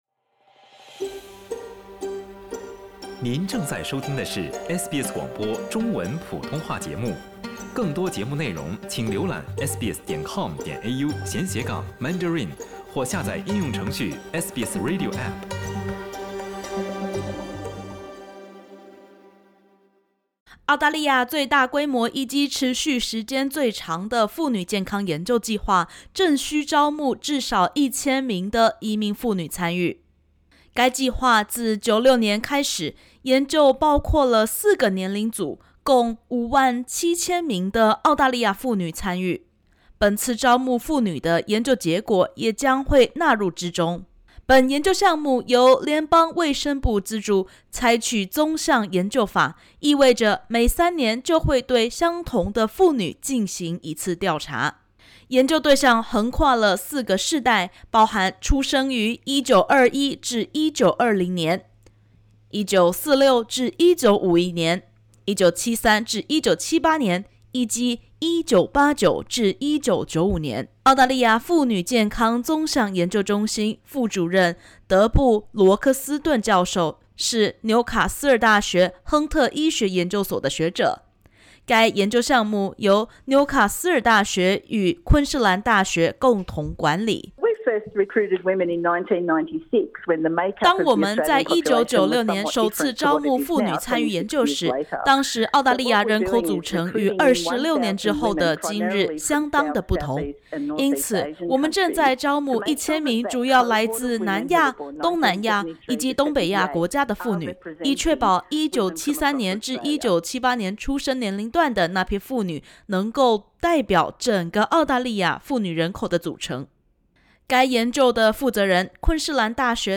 （点击首图收听采访音频）